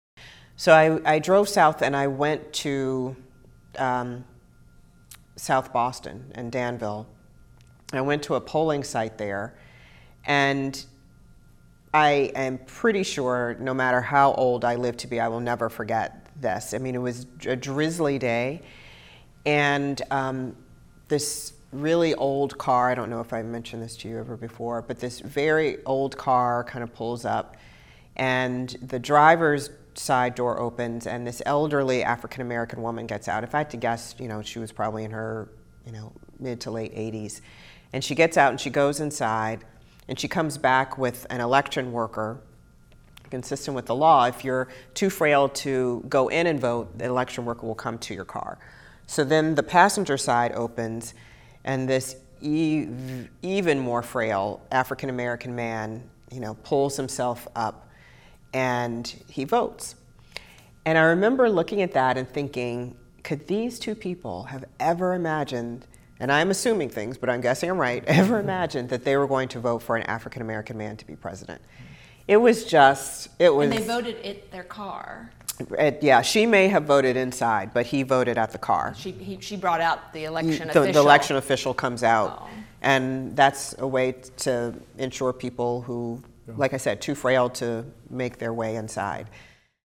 Barnes describes a memorable scene from election day in 2008. Date: June 12, 2019 Participants Melody Barnes Associated Resources Melody Barnes Oral History (Barack Obama) Melody Barnes Oral History (Edward M. Kennedy) Barack Obama Oral History Project Audio File Transcript